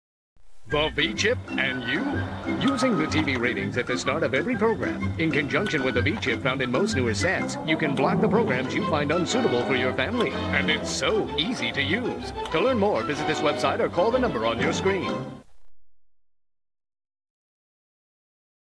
Video de promo de la Vchip capturée sur my9NY, filiale de la fox